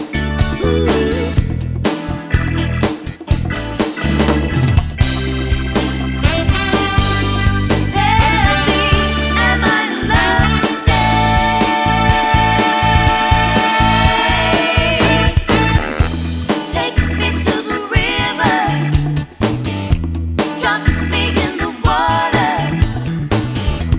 Live Pop (1960s) 3:30 Buy £1.50